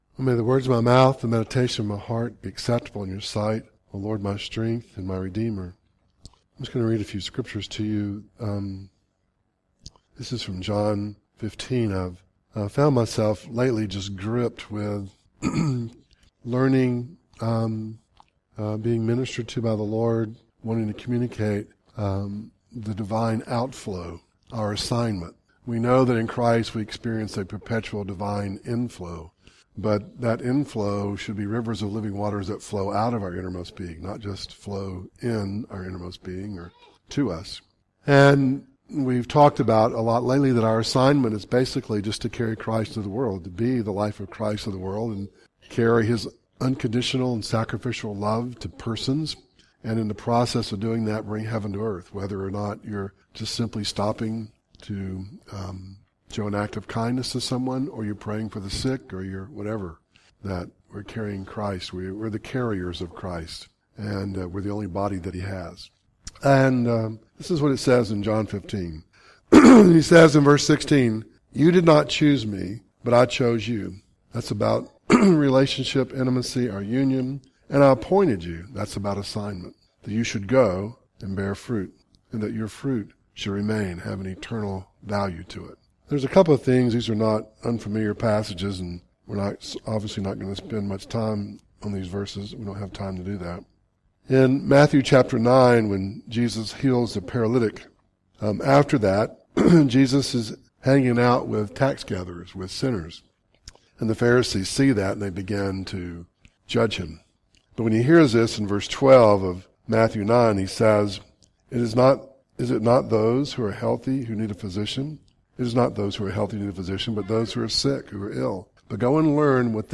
Series: Audio Devotionals